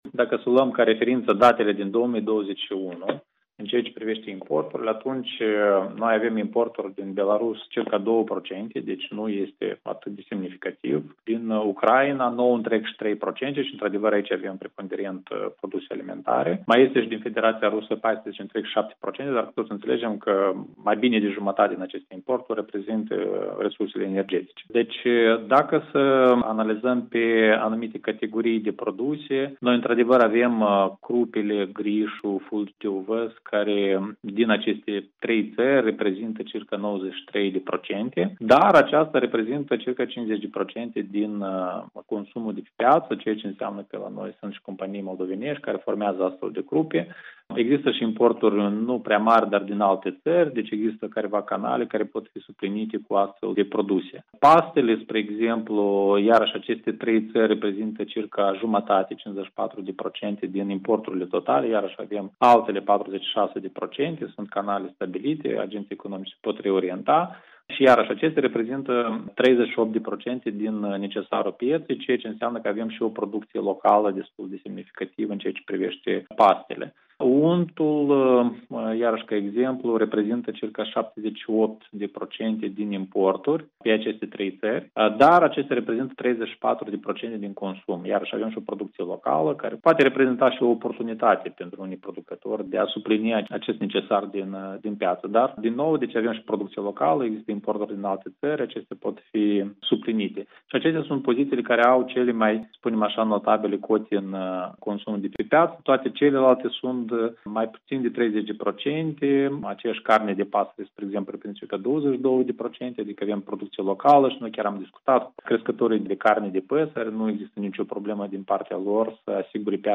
Iată situația de până la război, potrivit datelor furnizate Europei Libere într-un interviu de ministrul Gaibu: